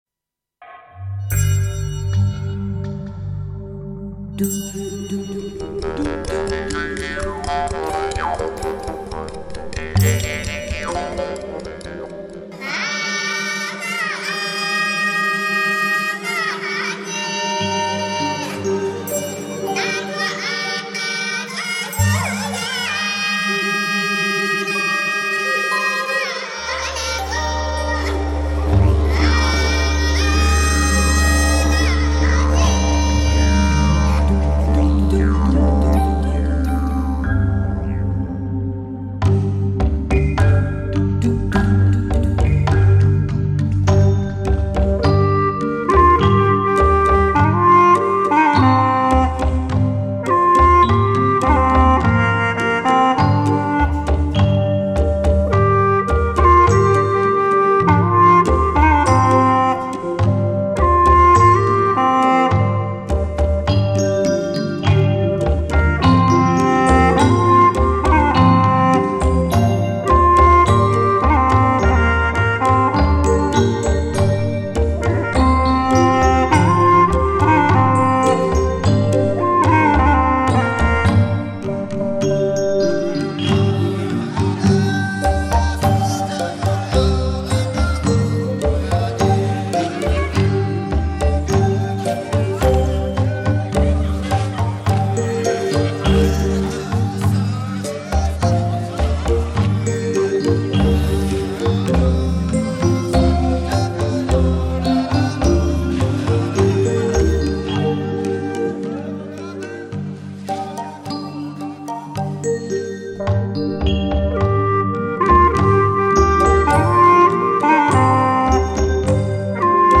调式 : 降B 曲类 : 民族